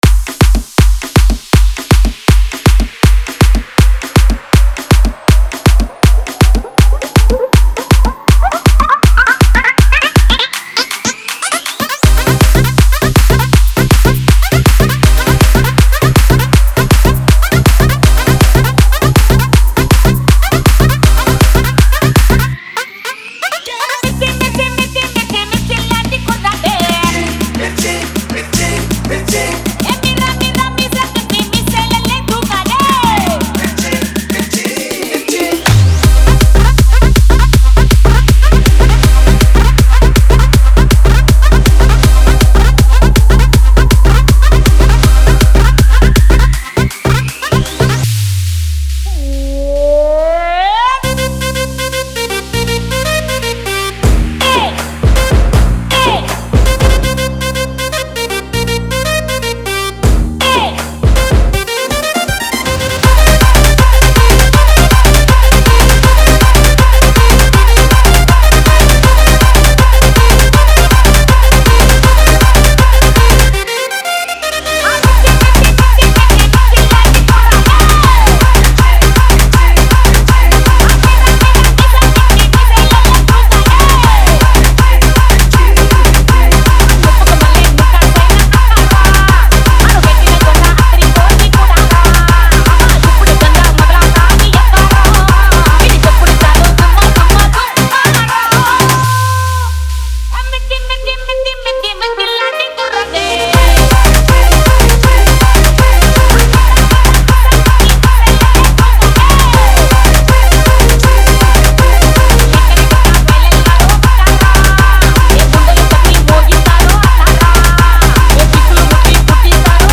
Category: Bbsr Spl All Dj Remix Songs 2021